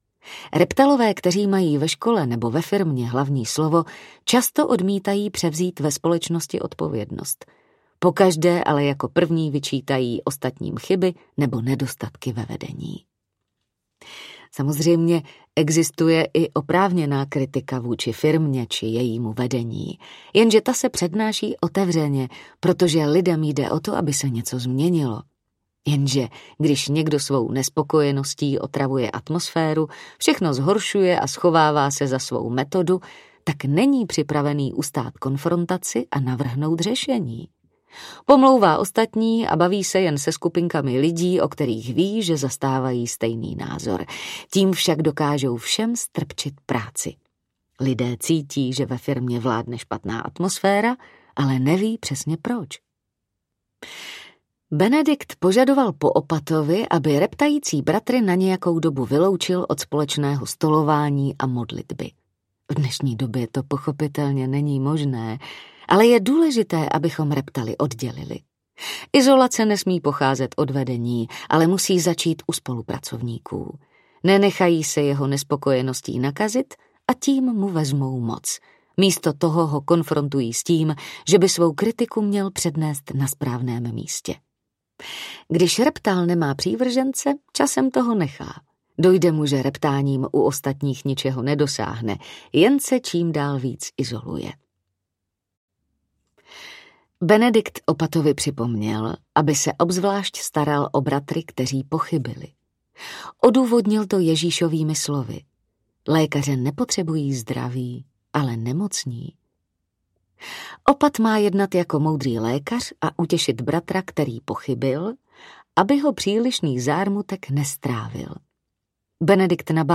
Audiokniha Cesta k radosti a vnitřnímu klidu - Anselm Grün | ProgresGuru